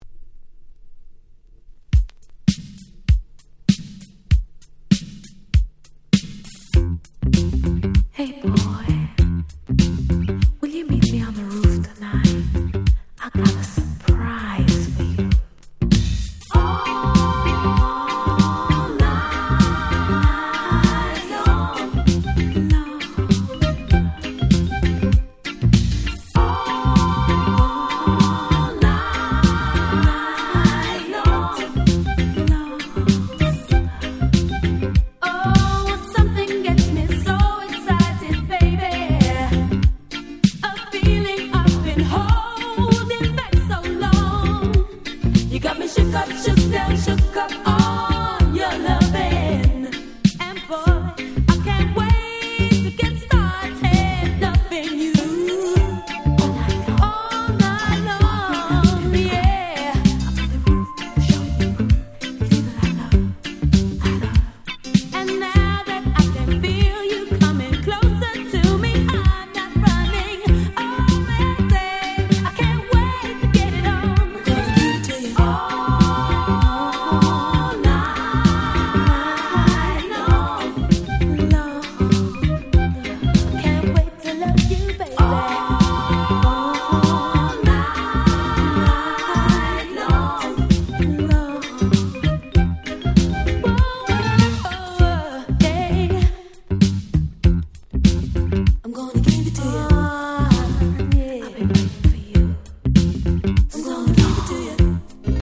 Very Nice Cover Reggae !!